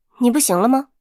尘白禁区_安卡希雅语音_登场2.mp3